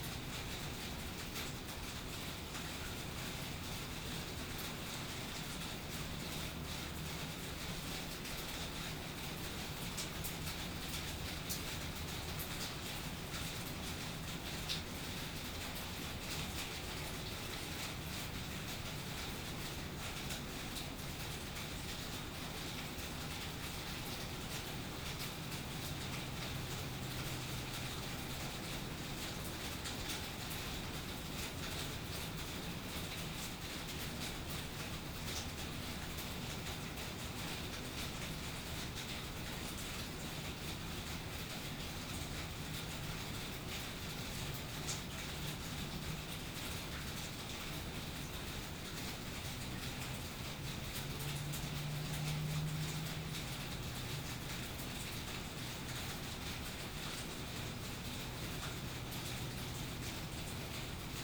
Weather Evening Suburbs Rainfall Concrete 04 BH2N_ambiX.wav